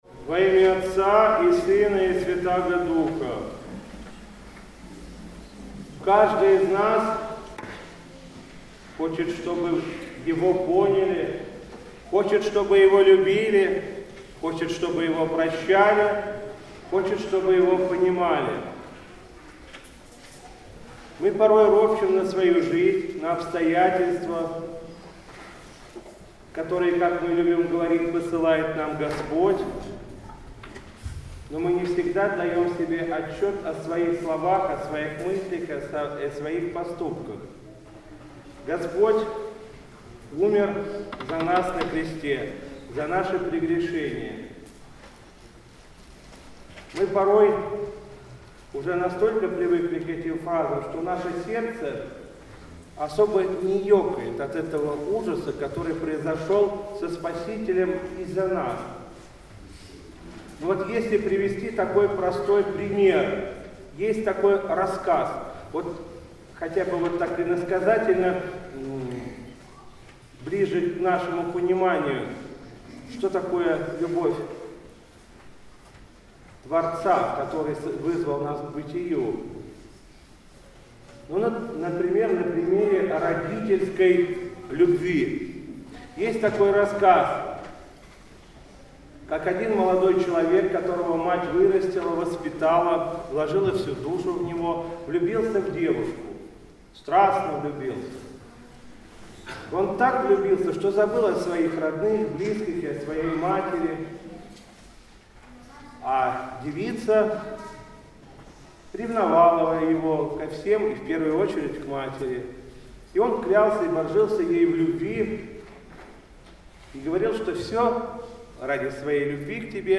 По окончании Литургии владыка Игнатий обратился к собравшимся в храме с архипастырским словом
Слово после Литургии Крестопоклонного воскресенья